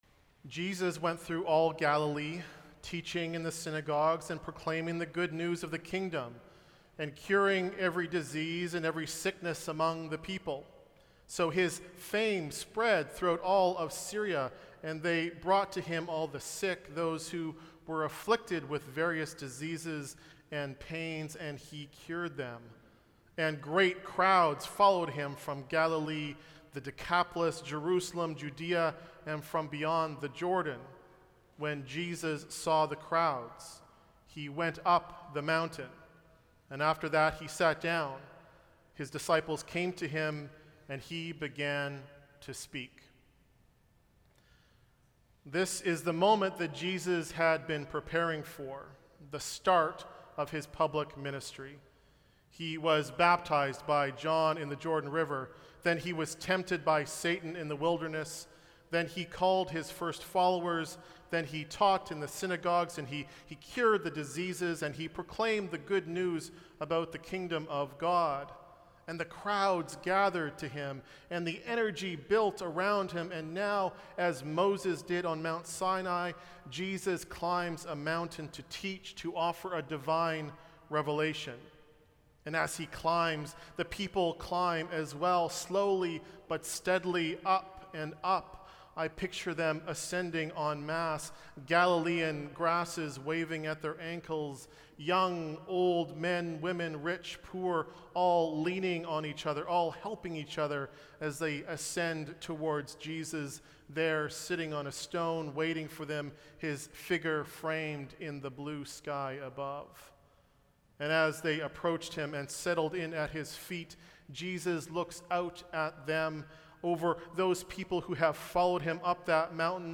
Sermon Notes